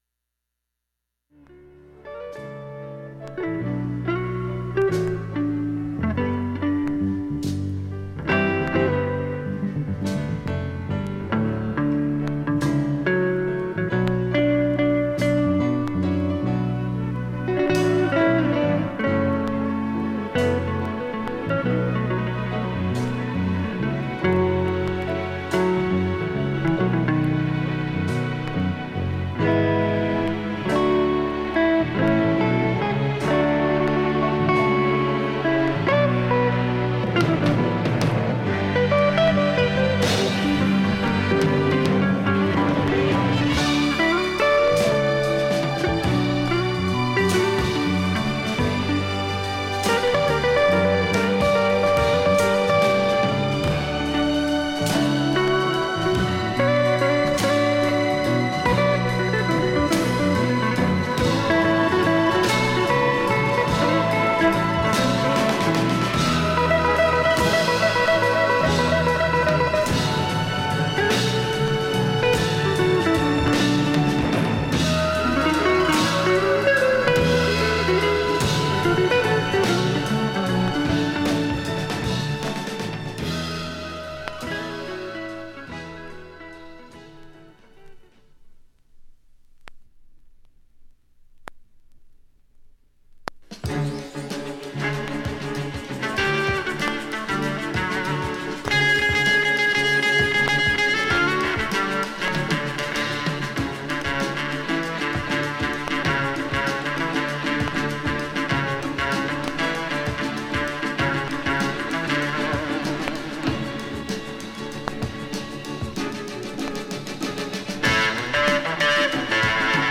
プツ音も小さめなので、
かすかなプツ１４回のみ。
盤面自体きれいでクリアないい音質